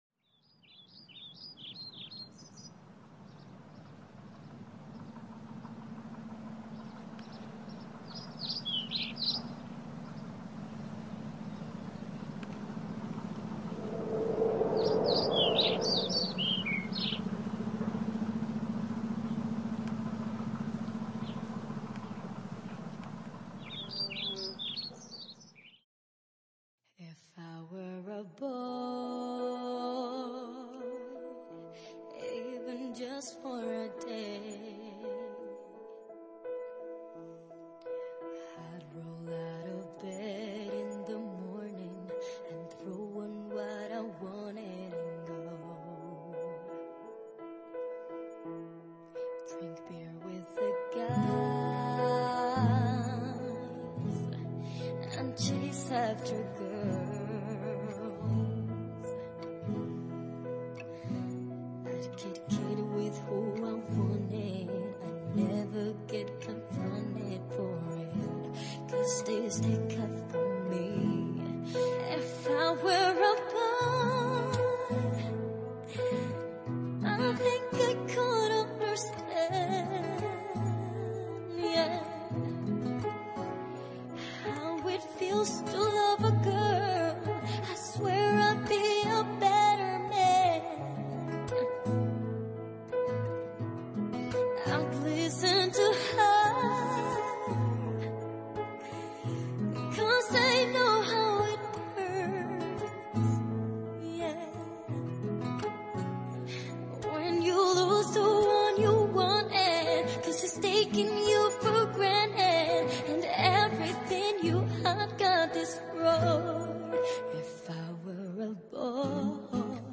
Cantante Soprano